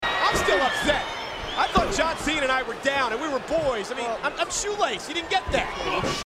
Michael Cole to nearly pee his pants in laughter.
I should note while this is happening, Cole starts talking about how